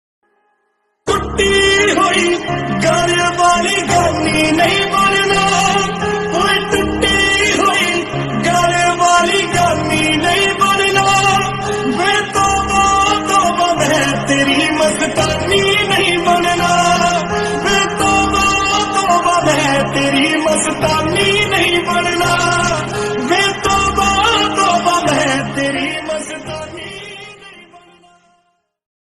love song ringtone